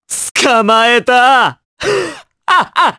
Dimael-Vox_Skill2_jp.wav